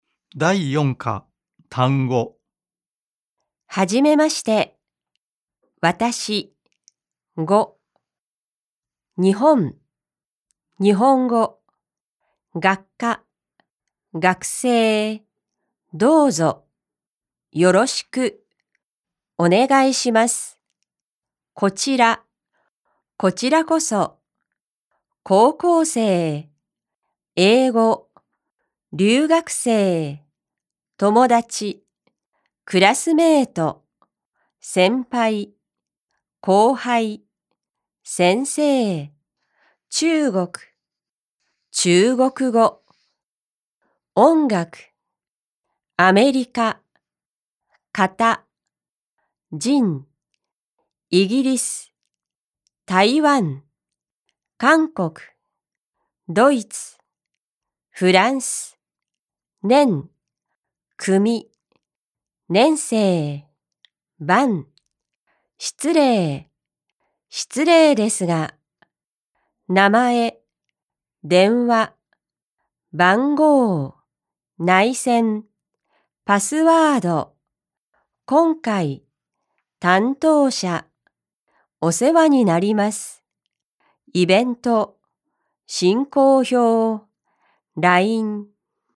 •有聲MP3：由專業日籍錄音老師所錄製的朗讀音源，收錄單字表、長會話、短會話、綜合練習等內容，提供教師配合課程進度在課堂上使用，學生也能在課後練習日語發音和語調。